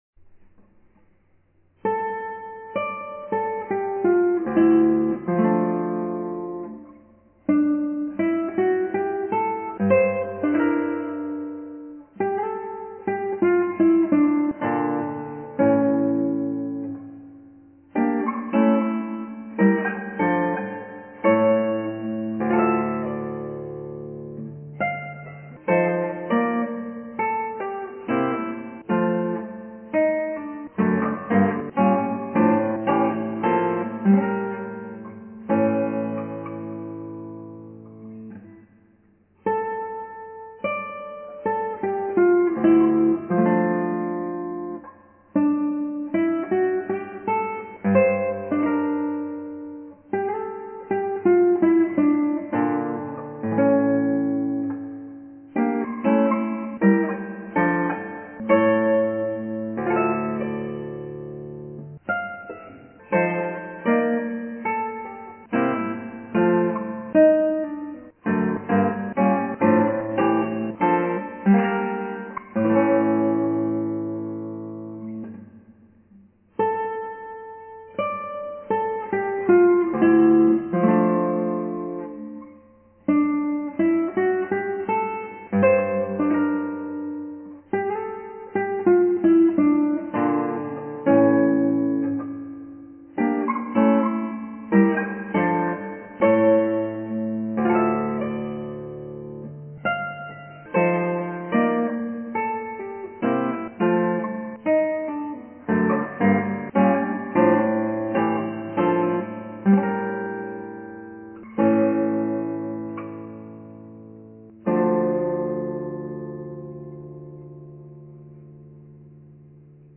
バルベロで